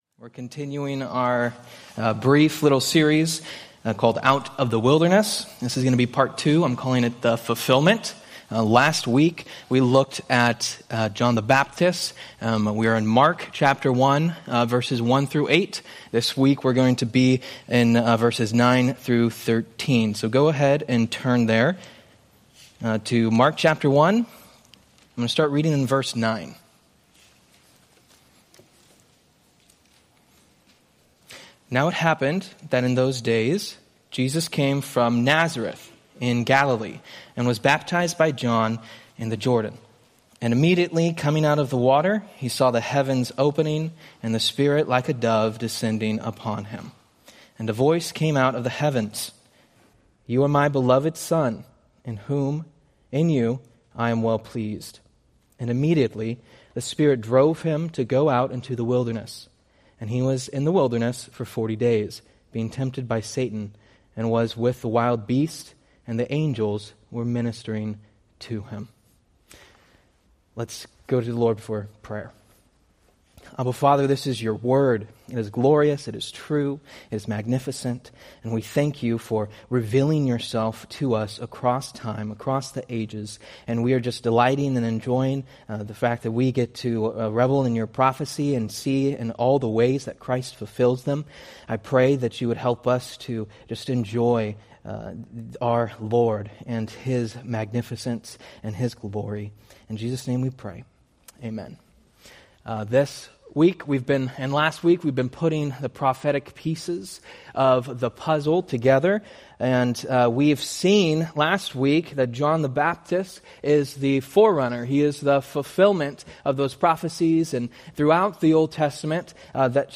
Date: Oct 5, 2025 Series: Various Sunday School Grouping: Sunday School (Adult) More: Download MP3 | YouTube